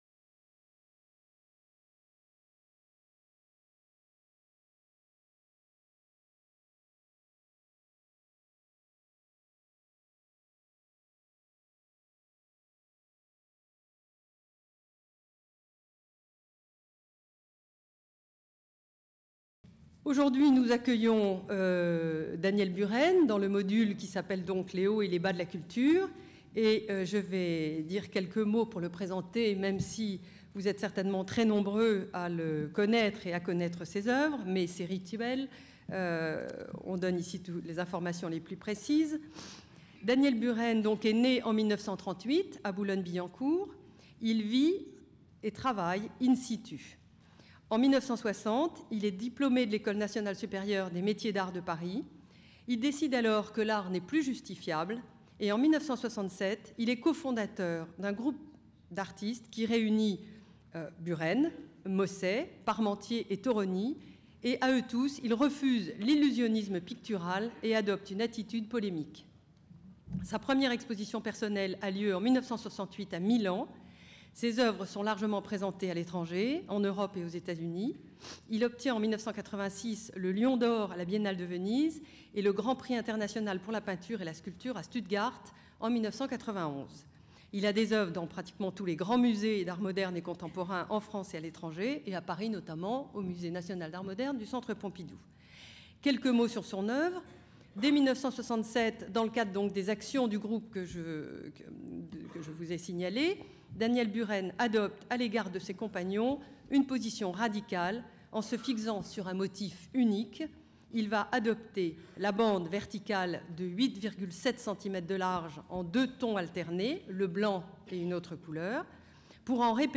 Artiste à l’origine de nombreuses polémiques notamment concernant la réalisation des « Colonnes de Buren » au Palais Royal de Paris, Daniel Buren nous livre lors de cette conférence son sens de l’art. Il décrit, décrypte certaines de ses réalisations, reflets de ses convictions artistiques.